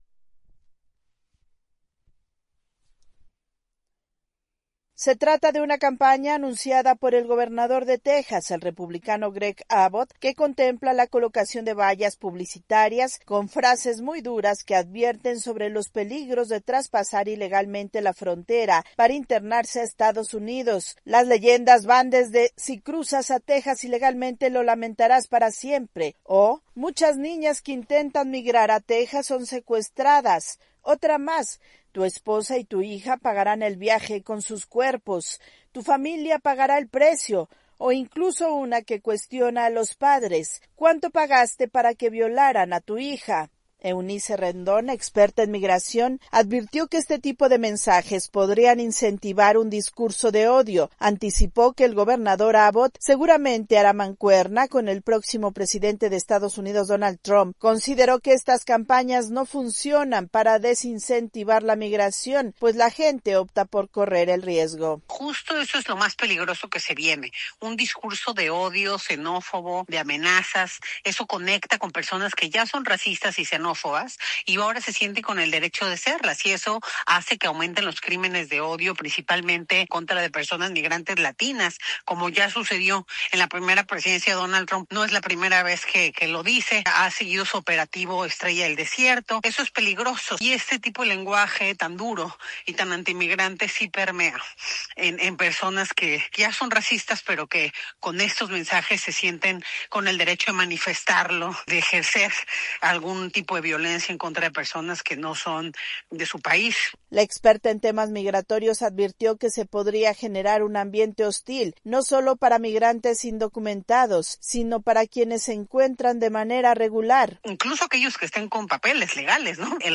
AudioNoticias
Más de 4 millones de venezolanos han podido regularizar su situación en América Latina, según la Organización Internacional para las Migraciones. Esta es una actualización de nuestra Sala de Redacción.